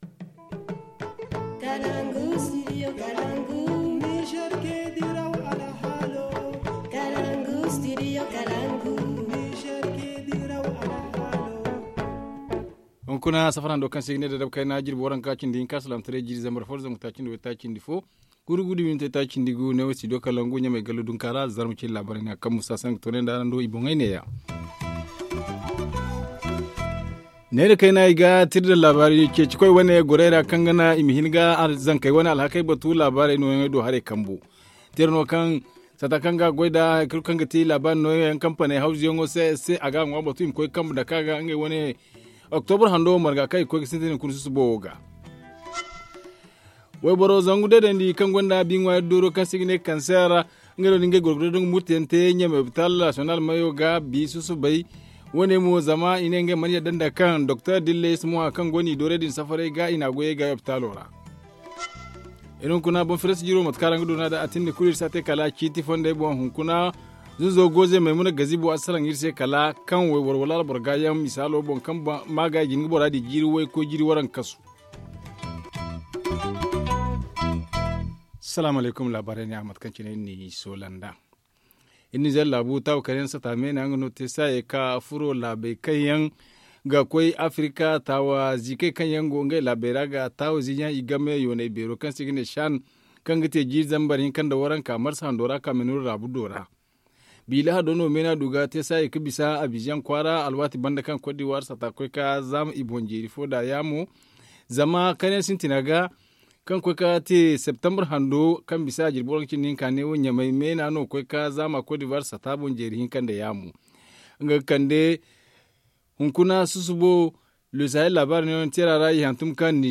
Le journal du 21 octobre 2019 - Studio Kalangou - Au rythme du Niger